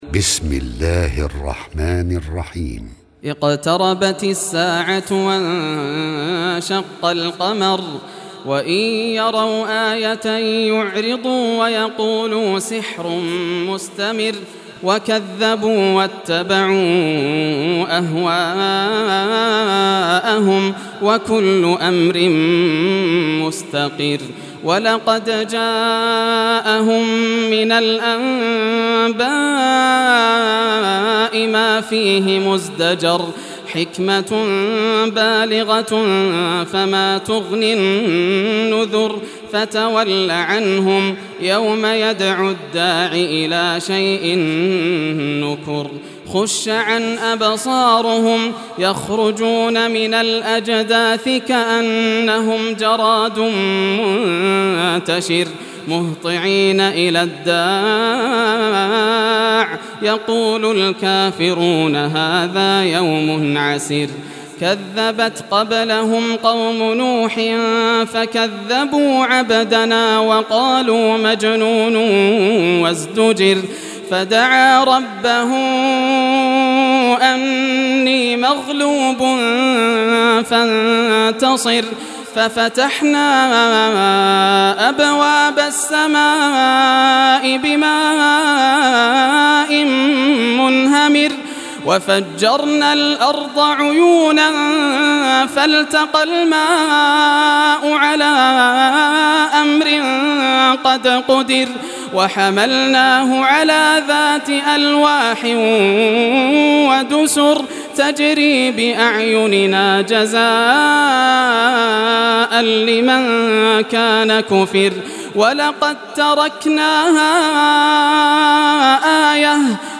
Surah Al-Qamar Recitation by Yasser al Dosari
Surah Al-Qamar, listen or play online mp3 tilawat / recitation in Arabic in the beautiful voice of Sheikh Yasser al Dosari.